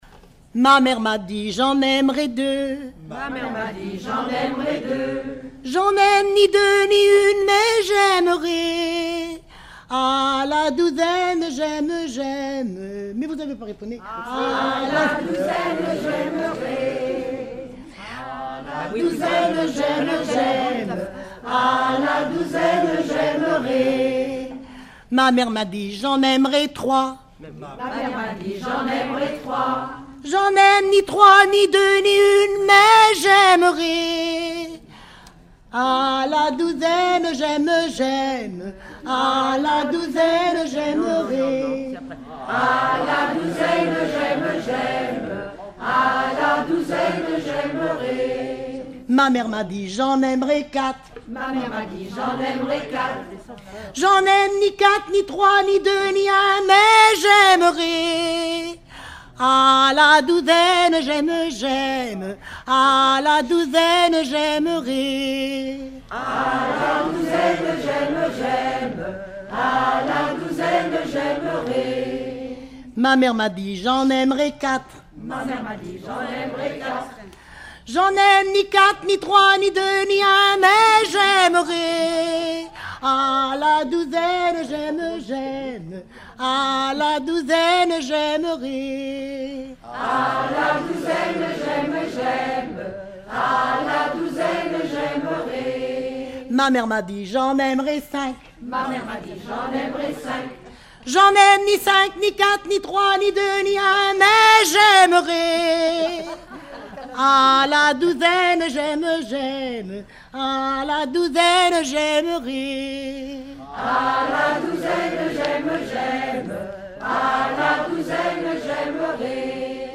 Genre énumérative
Regroupement de chanteurs du canton
Pièce musicale inédite